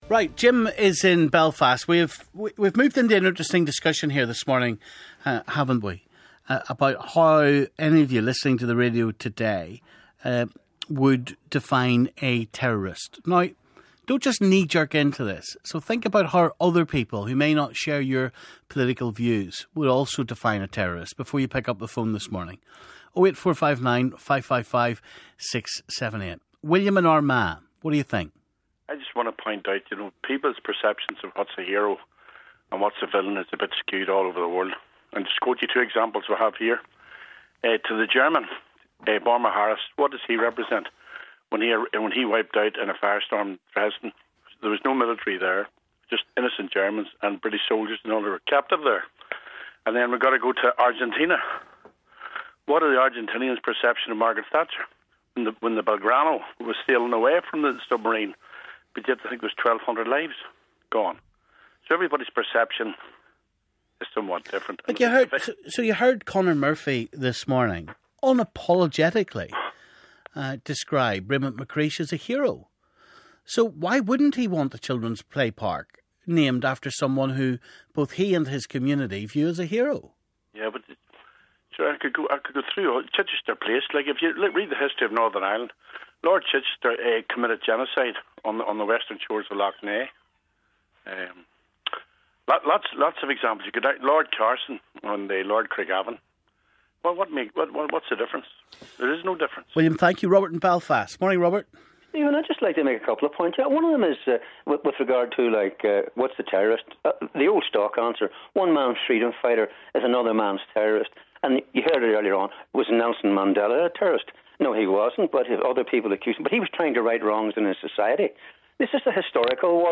How do you define a terrorist? BBC Nolan callers give their views